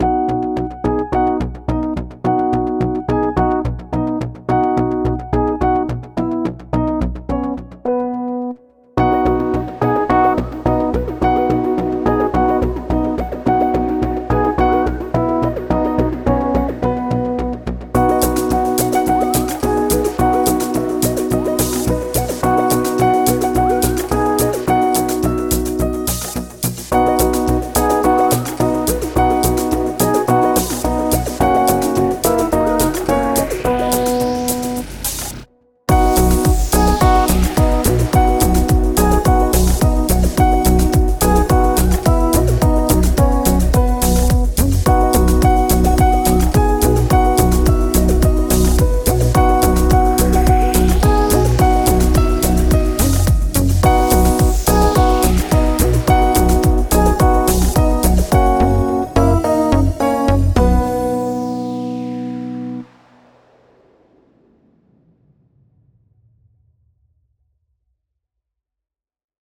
karaoke-versie